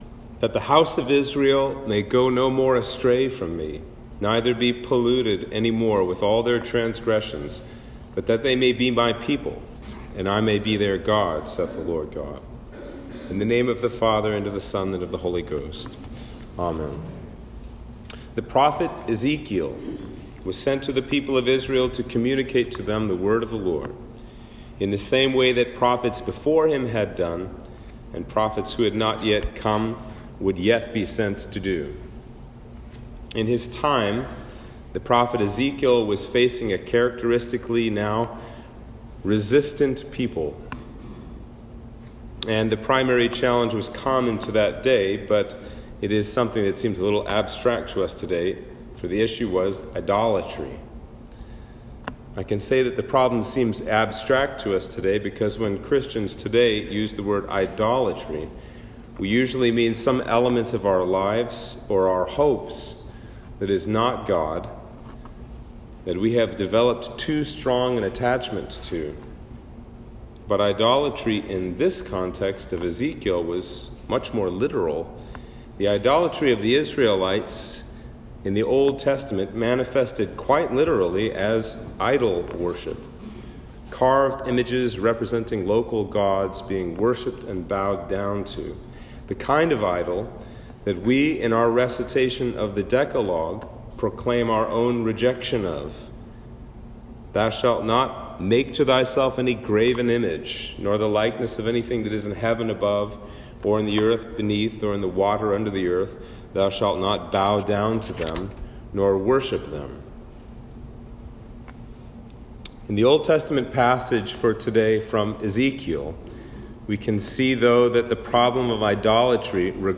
Sermon for Trinity 9